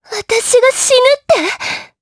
Erze-Vox_Dead_jp.wav